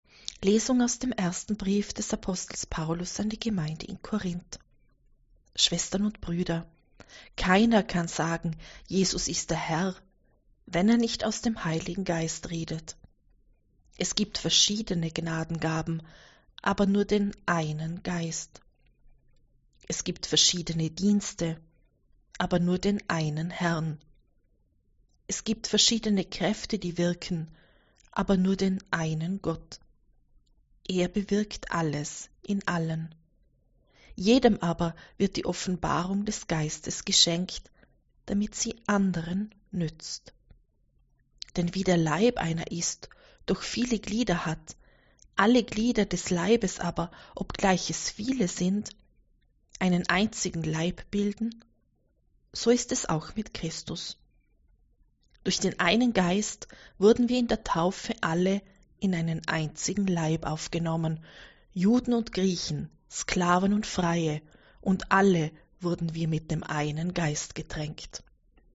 Wenn Sie den Text der 2. Lesung aus dem ersten Brief des Apostels Paulus an die Gemeinde in Korínth anhören möchten: